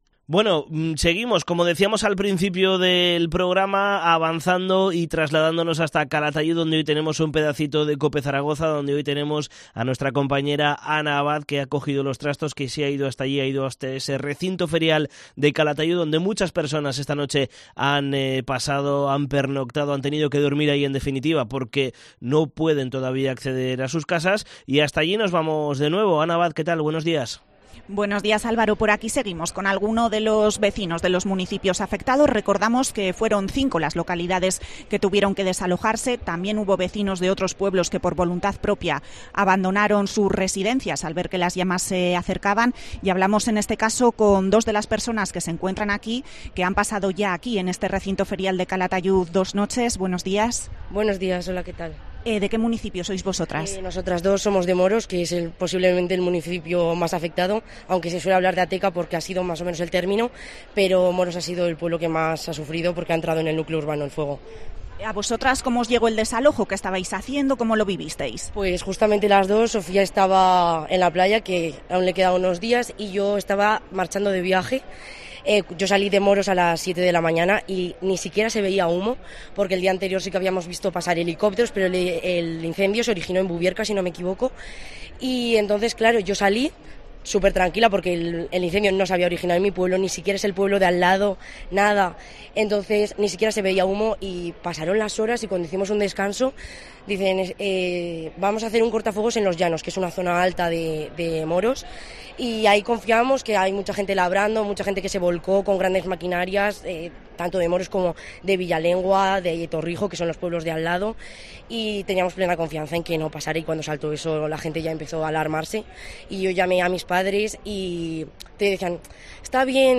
Dos vecinas de Moros nos cuentan cómo han vivido el desalojo del municipio debido al incendio de Ateca